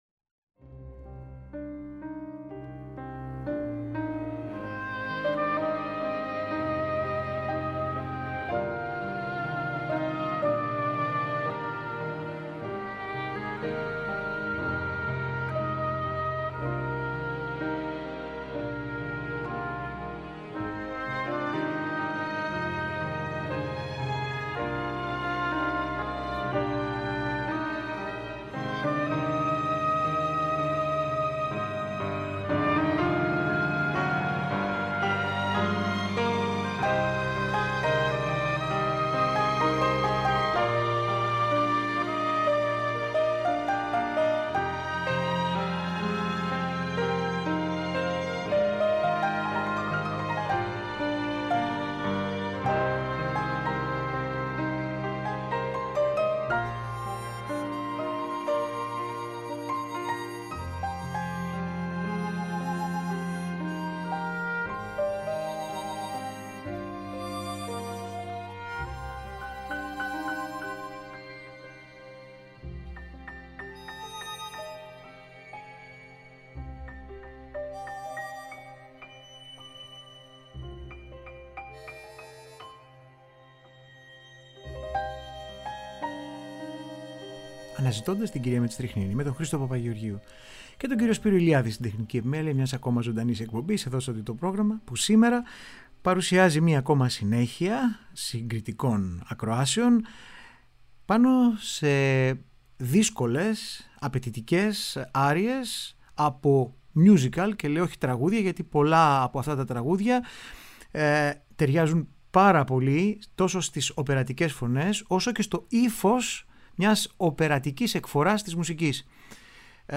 Σπουδαίες άριες μεγάλων απαιτήσεων από τα κορυφαία μιούζικαλ της διεθνούς σκηνής.